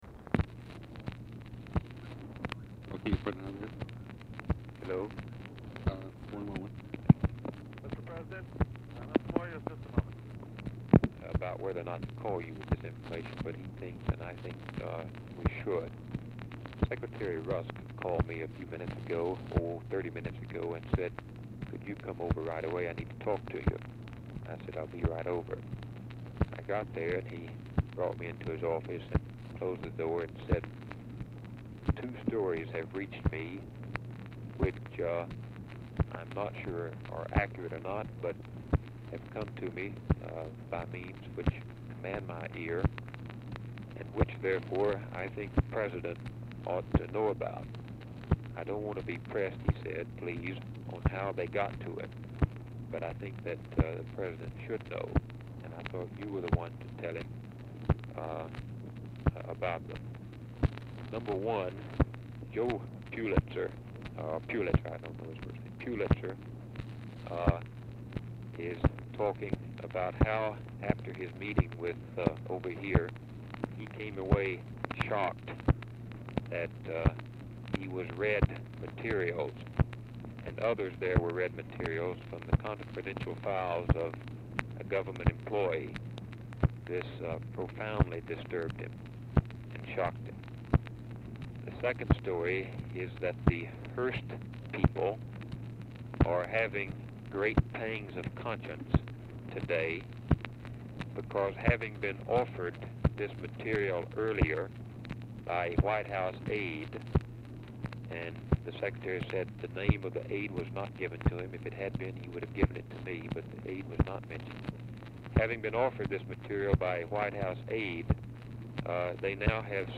LBJ Ranch, near Stonewall, Texas
Telephone conversation
Dictation belt